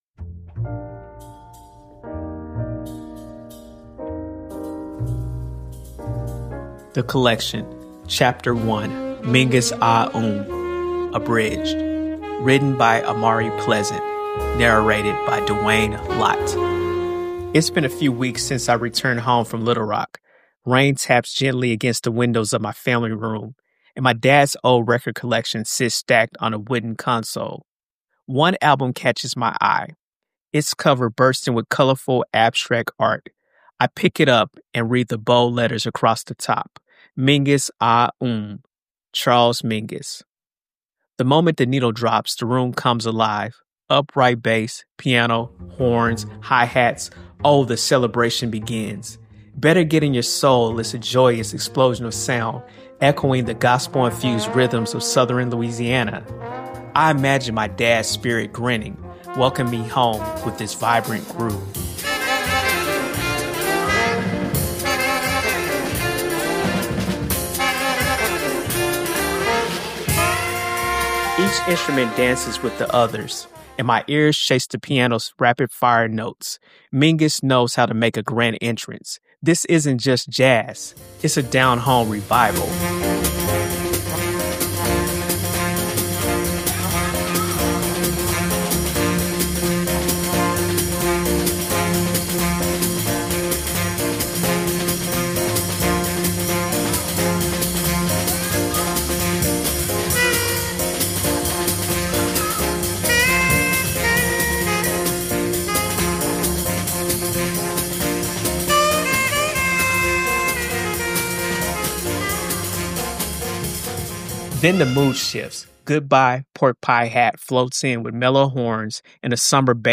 The Collection is an audio essay exploring a son's journey through his late father's records—connecting through music and reflecting on its meaning.